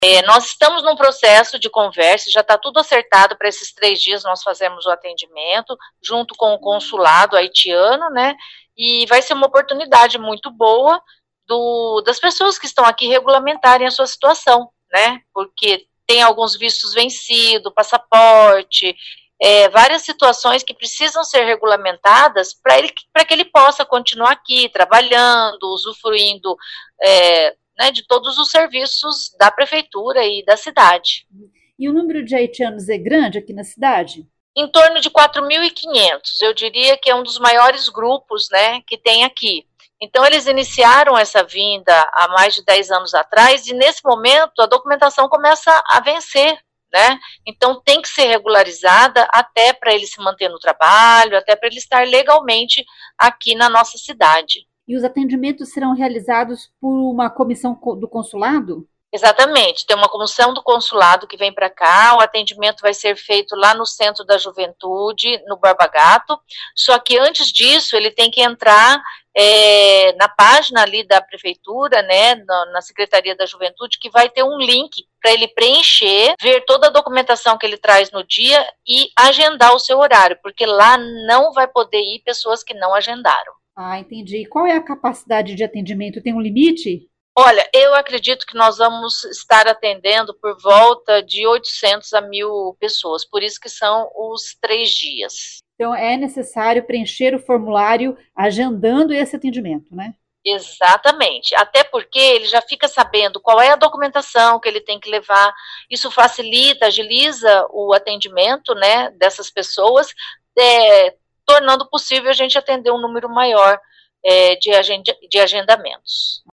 Ouça o que diz a secretária da Juventude, Cidadania e Migrantes de Maringá, Sandra Franchini: